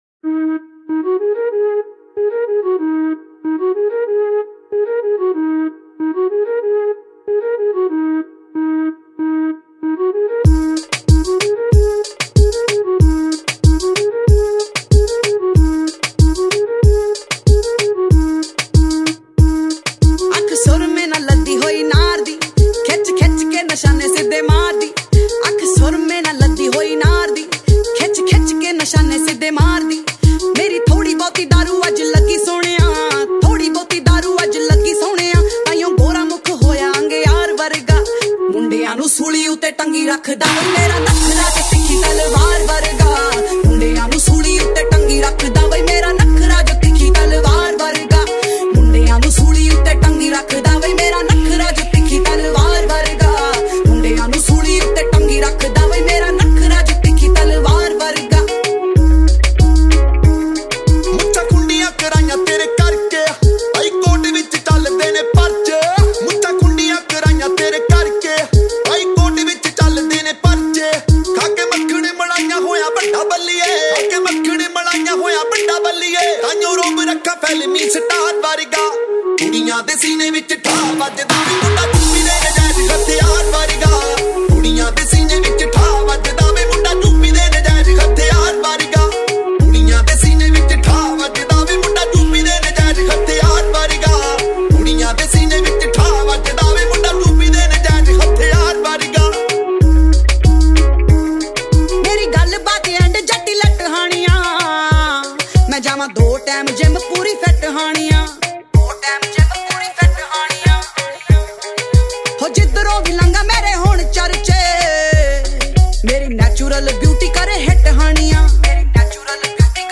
Punjabi Bhangra